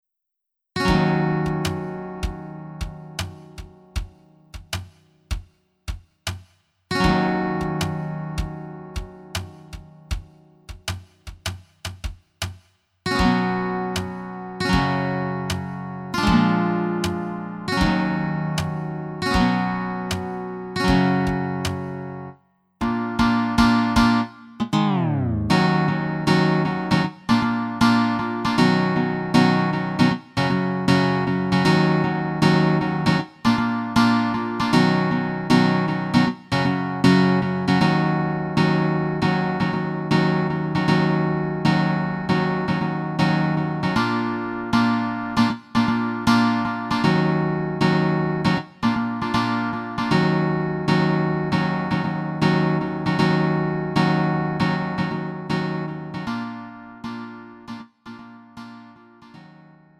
음정 -1키 2:51
장르 가요 구분 Lite MR